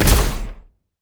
sfx_reinforce.wav